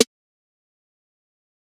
Migos Snare.wav